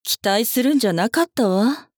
大人女性│女魔導師│リアクションボイス│商用利用可 フリーボイス素材 - freevoice4creators
がっかりする